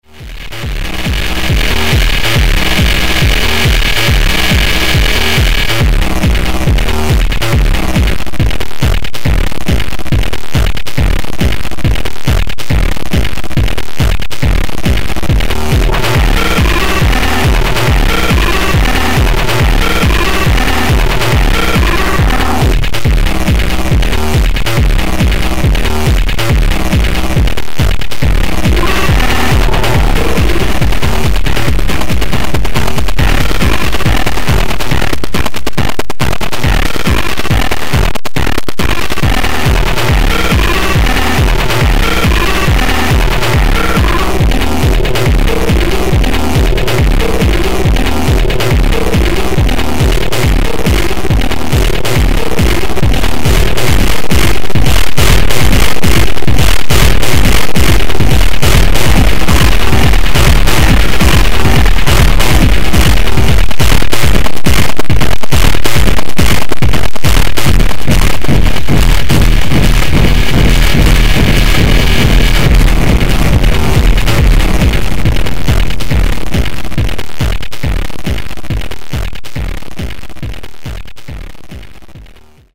Here's some cheesy dance music made with Reboy 1,0. It's pretty repetitive and it's just a test of the program.